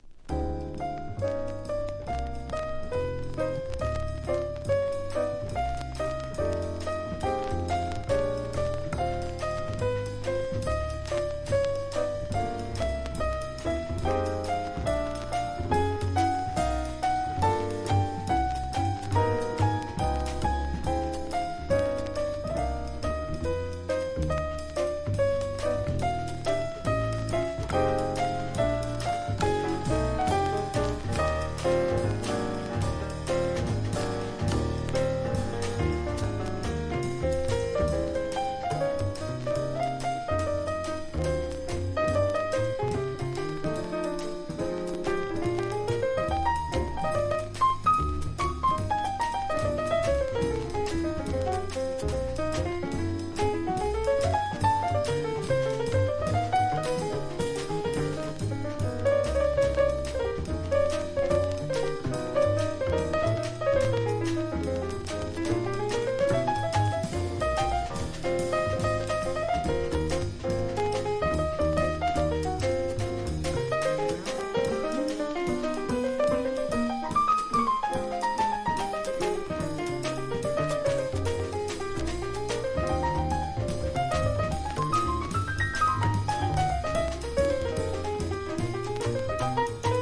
MODERN JAZZ# MODAL